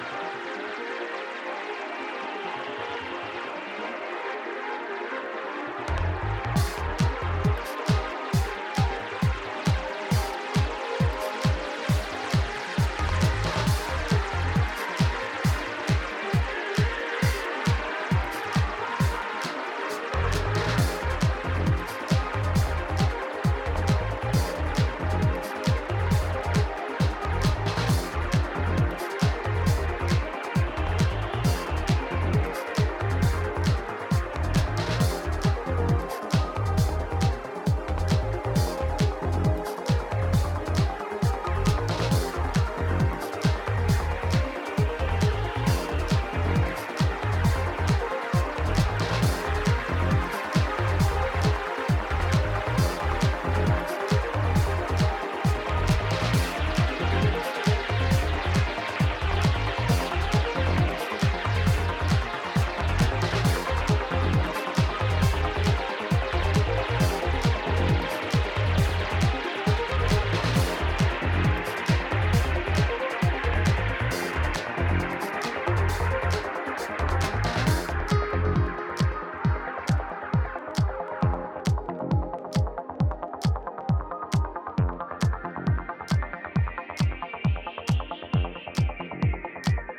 ピッチを上げたジャジー・ハウスにトランシーなシンセアルペジオを足したような